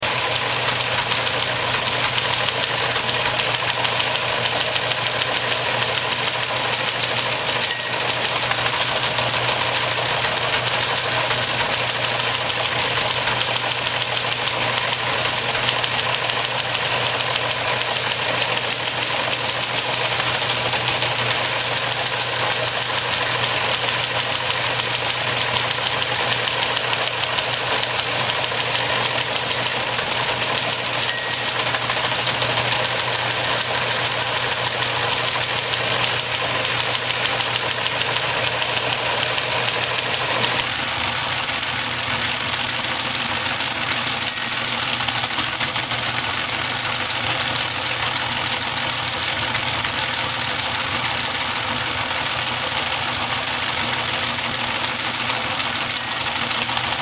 Model 20 Teletype Machine
This is an old electro-mechanical Teletype machine (Model 20) printing out a news feed. These machines were used by newspapers and radio stations to receive news stories from organisations like the Associated Press and United Press International (UPI). They were made obsolete by computerized news delivery and were largely gone by the late 70s.